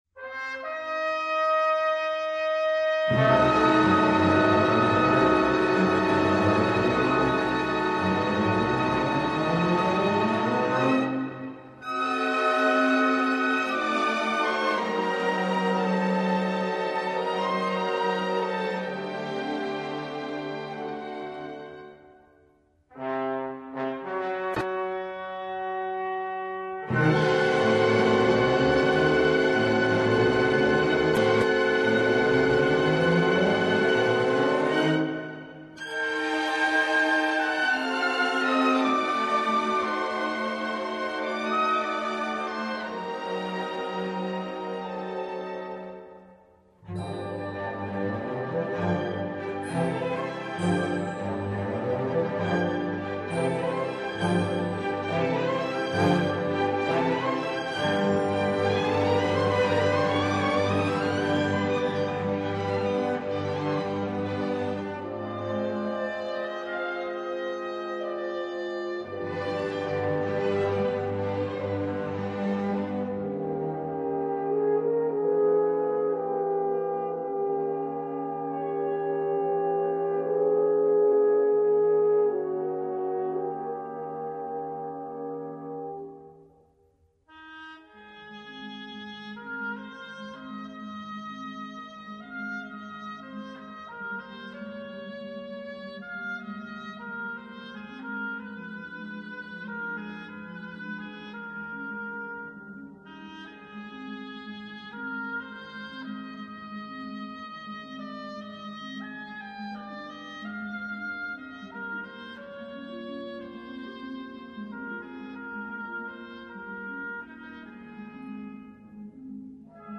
Preludio